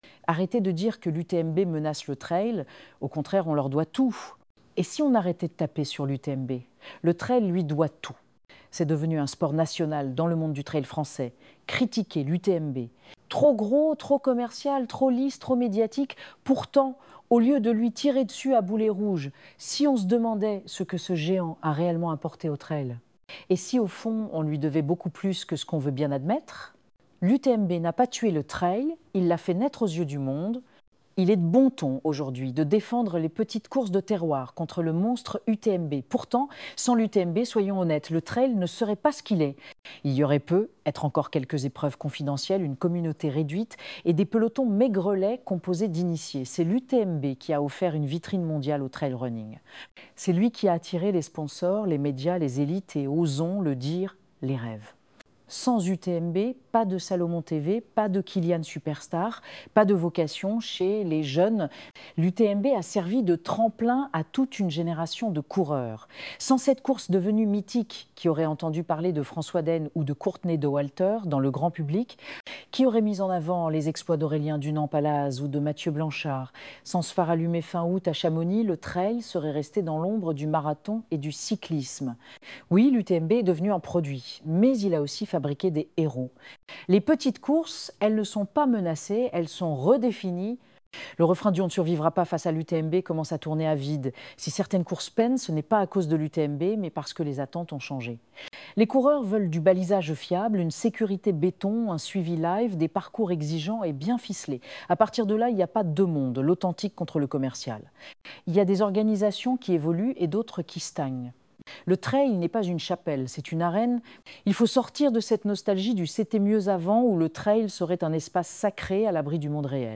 écouter cet article sur l’UTMB